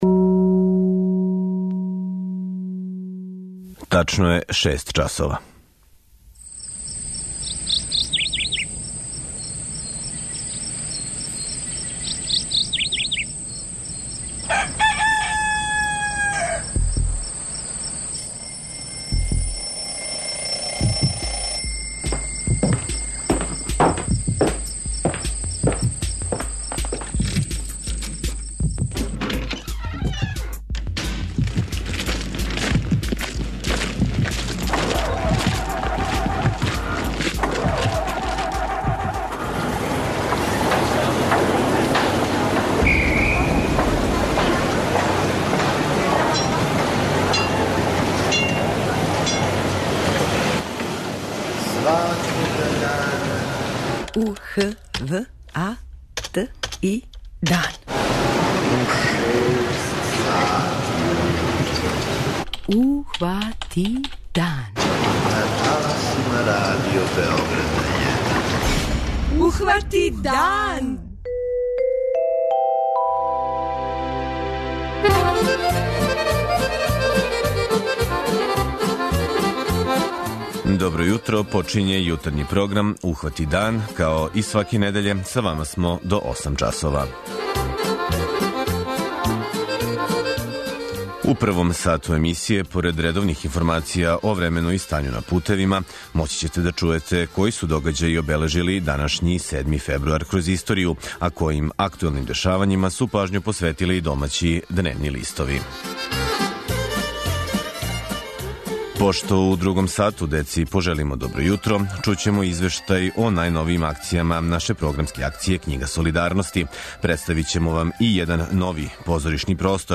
преузми : 57.26 MB Ухвати дан Autor: Група аутора Јутарњи програм Радио Београда 1!